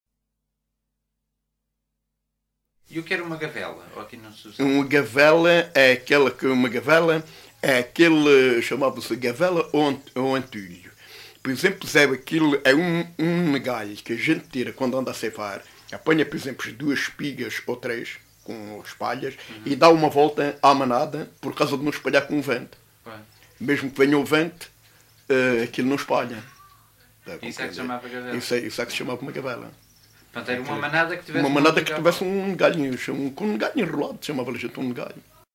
LocalidadeSapeira (Castelo de Vide, Portalegre)